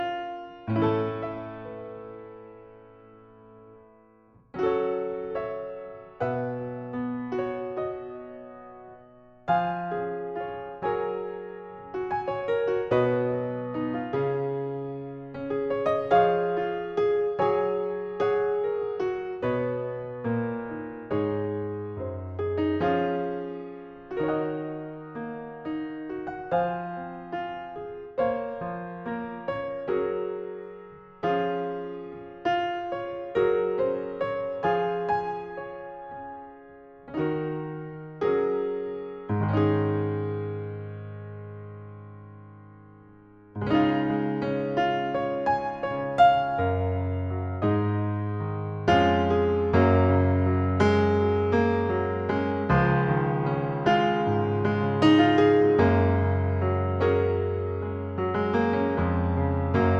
Live Version Duets 4:29 Buy £1.50